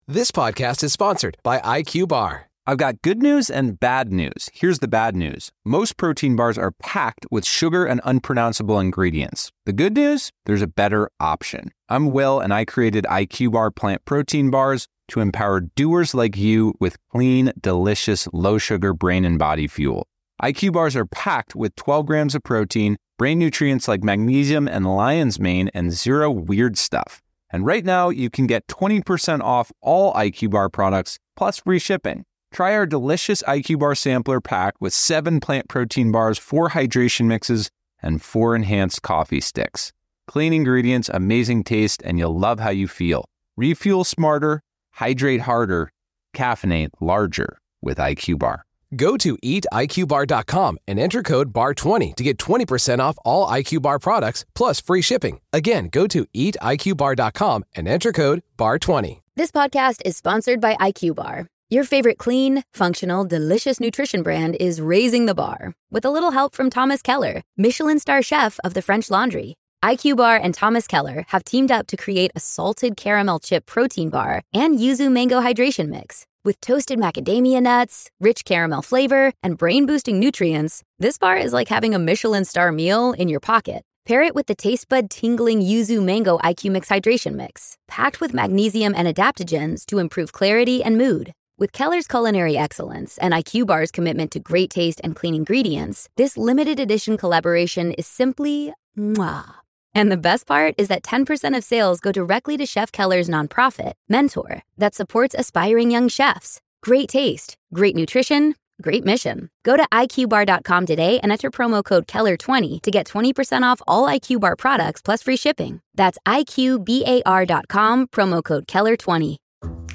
Ashleigh Banfield is joined by former inmate and prison consultant